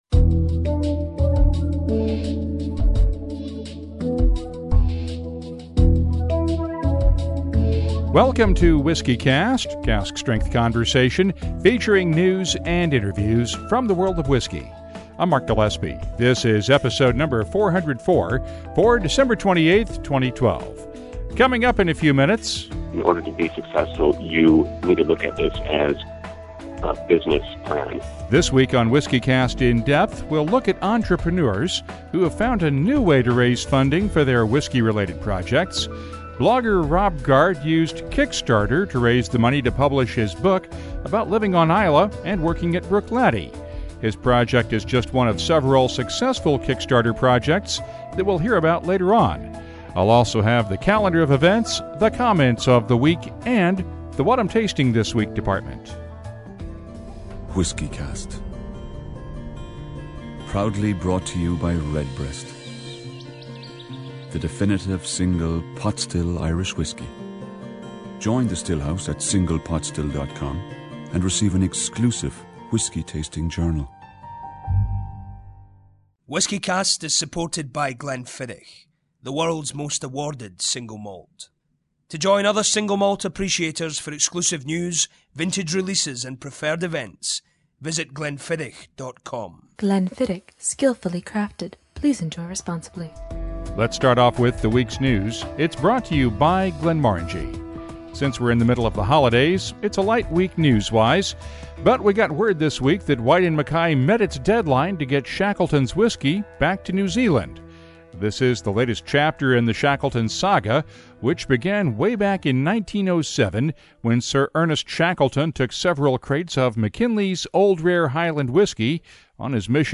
interview at the 25:15 mark